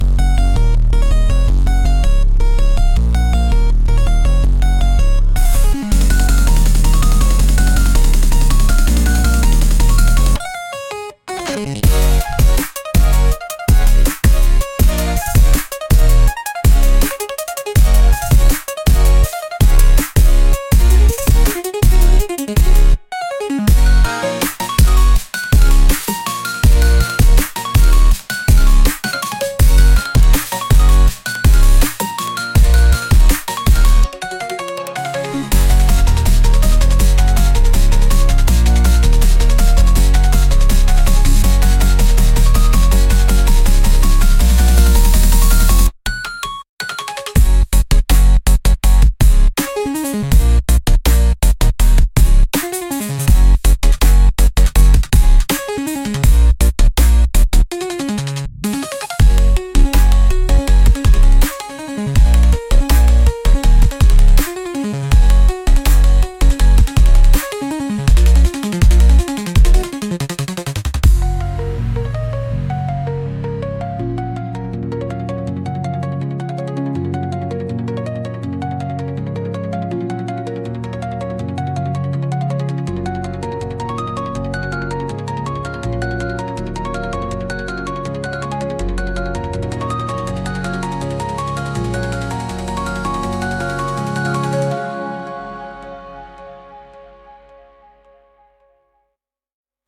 Genre: Futuristic Mood: Dreamlike Editor's Choice